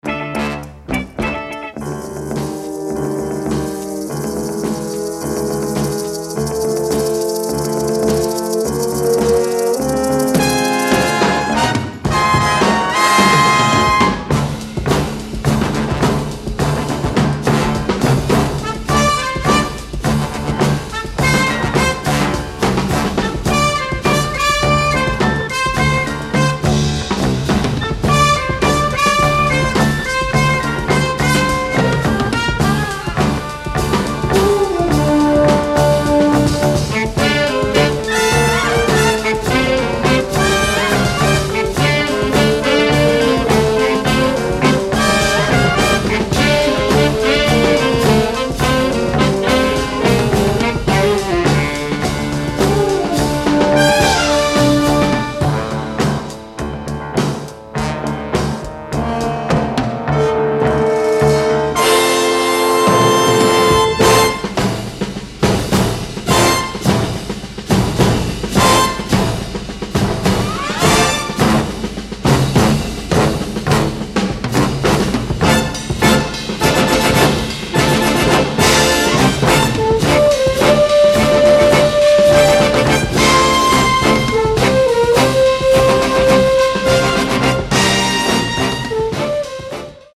original score tracks from 70s era cop/detective series